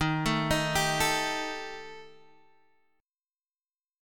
D# Major Flat 5th